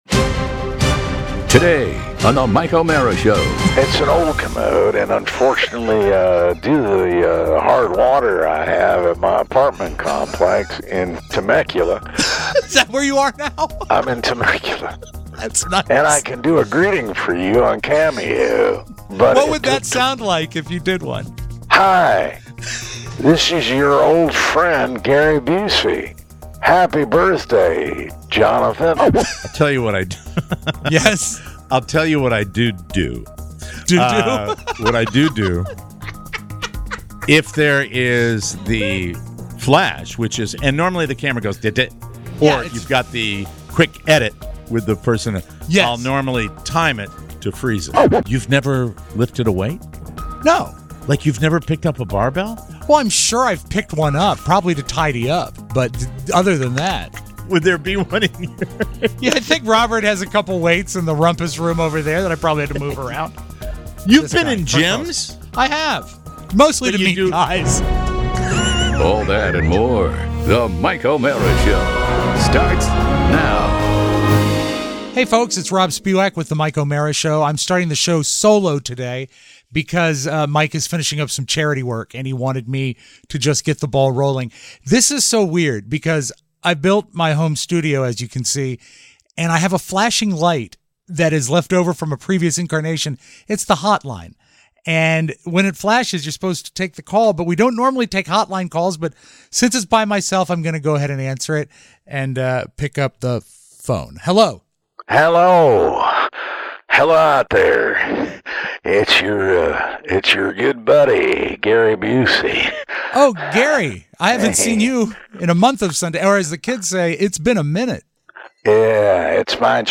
Today, a surprise phone call from Temecula lends some positive advice that we can all understand.